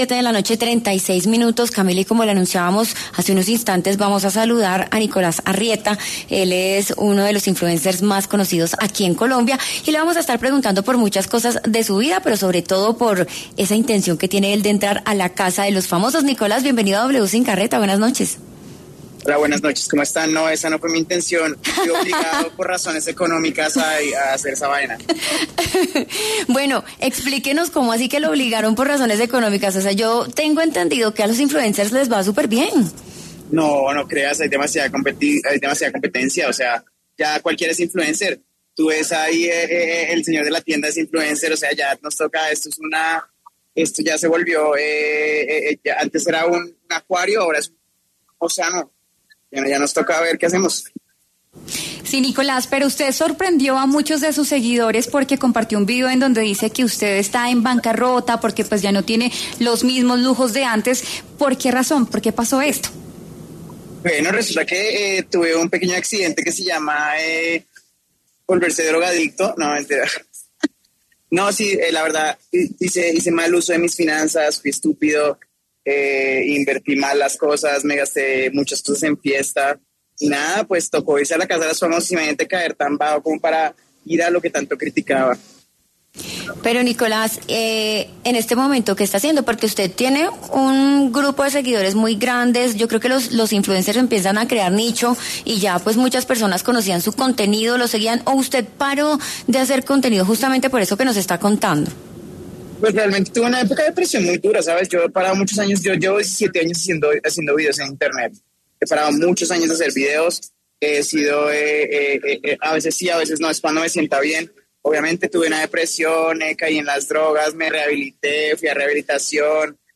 Por este motivo, el mismo Arrieta habló en los micrófonos de W Sin Carreta este viernes, 24 de octubre, y dio las razones que lo llevaron a tomar la decisión.
“Esa no fue mi intención (entrar al reality). Fui obligado por razones económicas para hacer esa vaina”, dijo en su primera intervención entre risas.